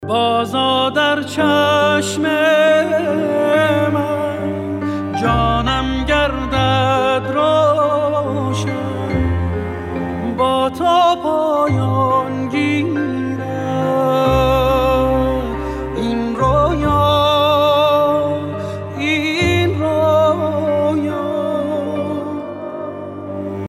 با کلام ملایم و رمانتیک